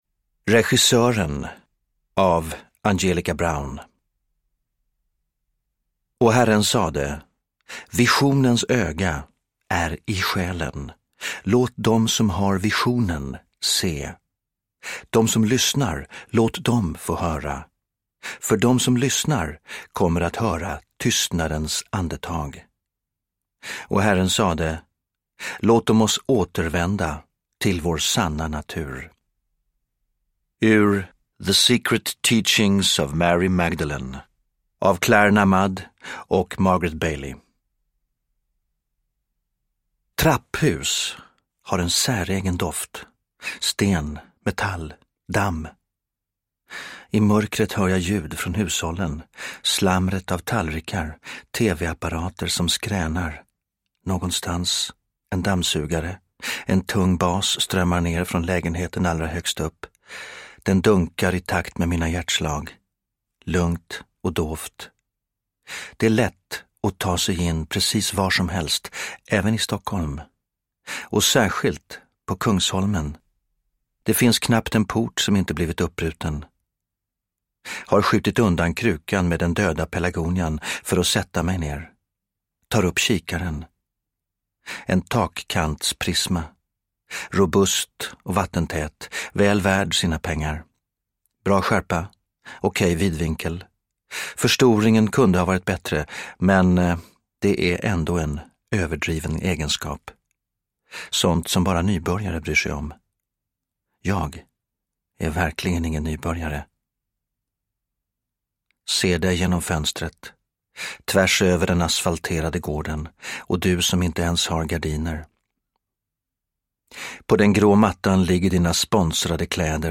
Regissören – Ljudbok – Laddas ner
Uppläsare: Stefan Sauk